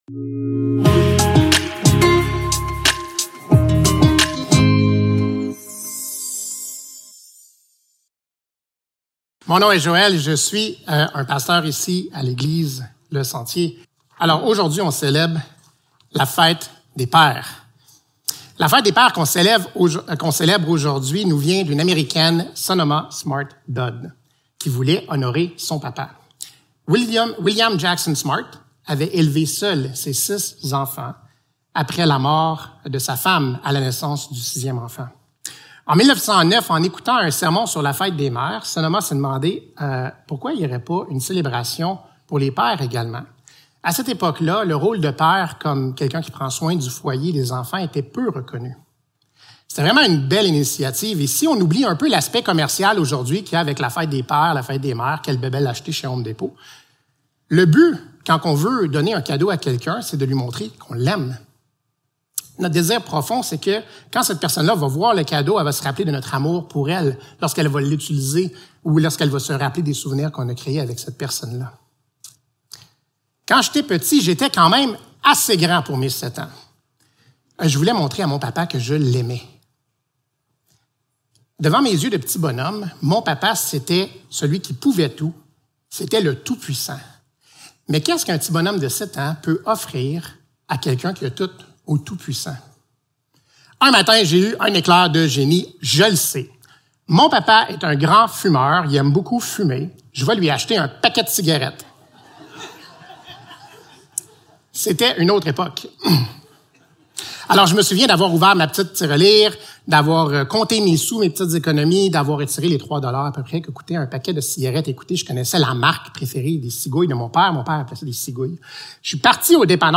Deutéronome 6.1-25 Service Type: Célébration dimanche matin Description